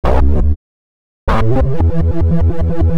The stalker (Bass) 120BPM.wav